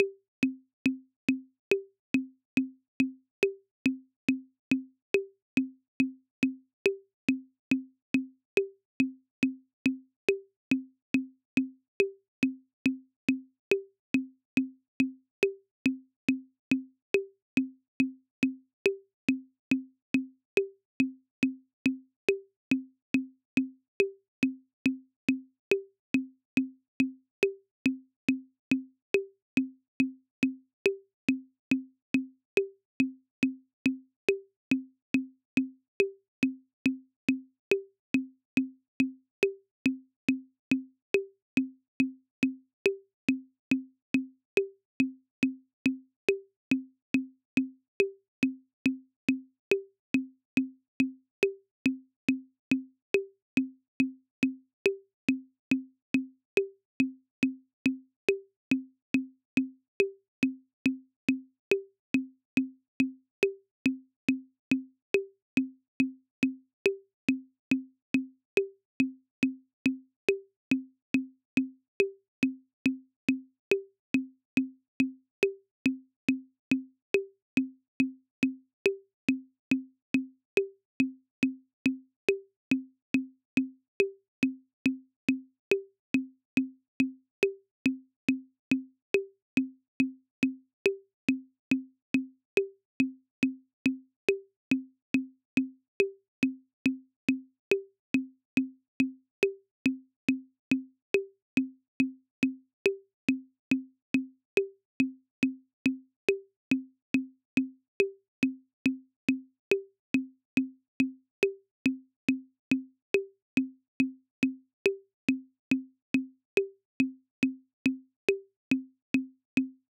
Ys Click 170bpm
Ys-click-170bpm.wav